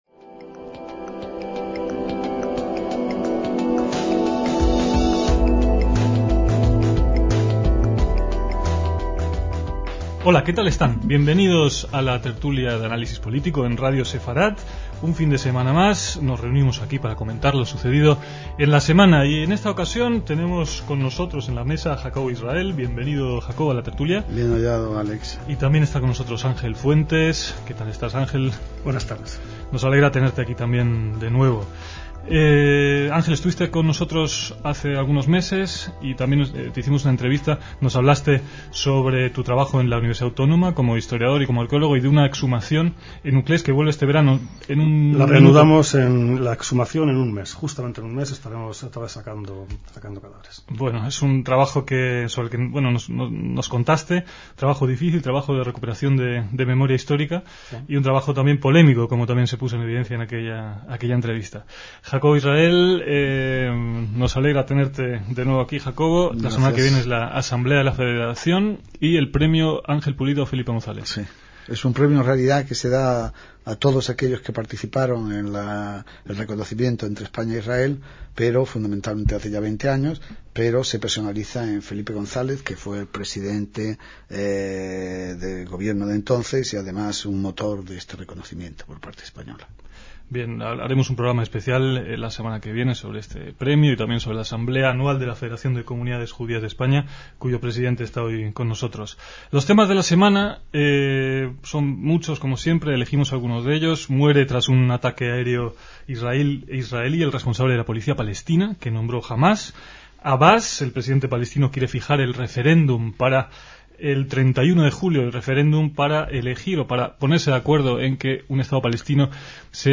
DECÍAMOS AYER (10/6/2006) - En la mesa redonde semanal de Radio Sefarad en la fecha señalada se habló sobre un tema cercano como españoles y como judíos: ¿hay que negociar con los terroristas?